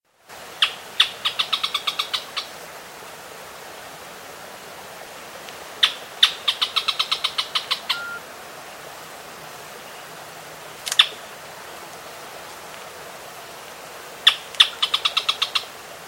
Tacuarero (Clibanornis dendrocolaptoides)
Tacuarero---Misiones_2015-jul_317.mp3
Nombre en inglés: Canebrake Groundcreeper
Fase de la vida: Adulto
Localidad o área protegida: Parque Provincial Araucaria
Condición: Silvestre
Certeza: Observada, Vocalización Grabada